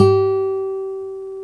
Index of /90_sSampleCDs/Roland L-CD701/GTR_Nylon String/GTR_Nylon Chorus
GTR NYL3 G0P.wav